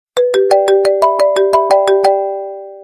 . SMS hangok .
Iphone_Sms.mp3